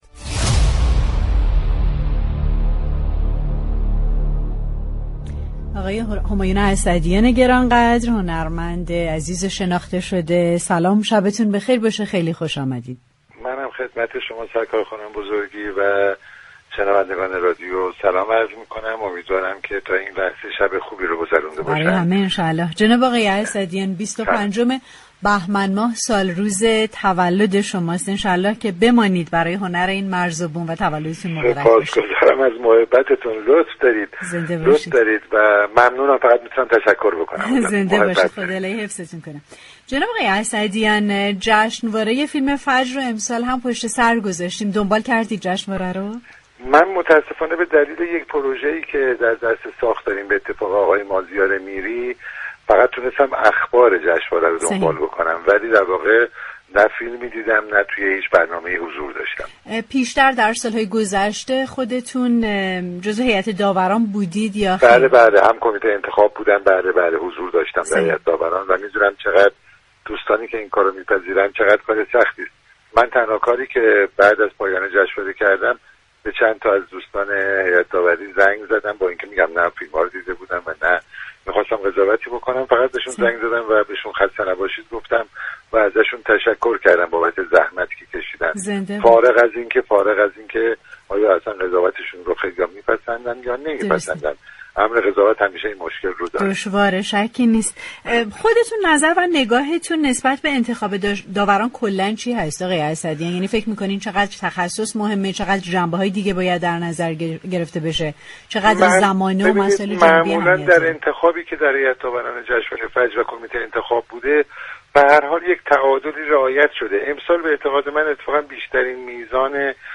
به گزارش پایگاه اطلاع رسانی رادیو تهران، 25 بهمن ماه، زادروز همایون اسعدیان، نویسنده، كارگردان و تهیه كننده كشورمان است و به همین مناسبت وی در این شب مهمان تلفنی برنامه پشت صحنه رادیو تهران بود.